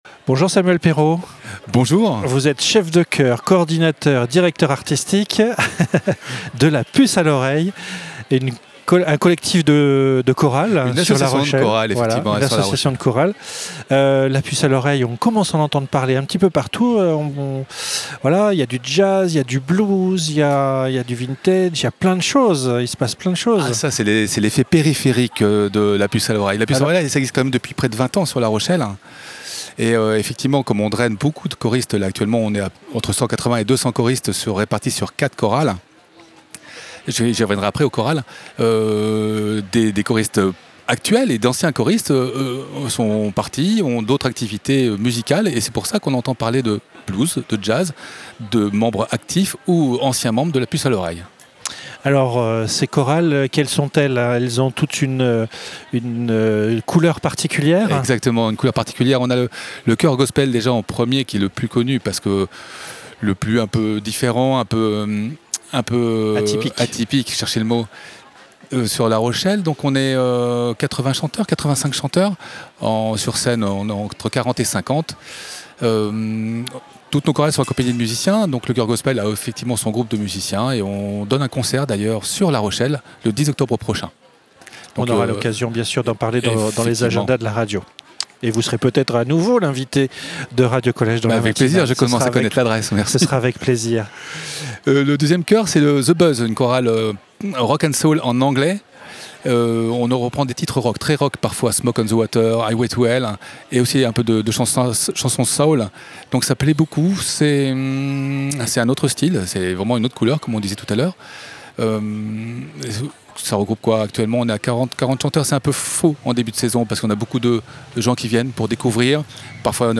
Lors du Village de la Vie Associative qui se tenait le 6 septembre dernier à La Rochelle, nous avons eu l’occasion de mettre en avant différentes associations.
L’interview est à retrouver ci-dessous.